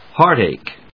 héart・àche